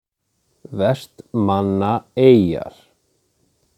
Vestmannaeyjar (Icelandic pronunciation: [ˈvɛstˌmanːaˌeiːjar̥]
Vestmannaeyjar_pronunciation.ogg.mp3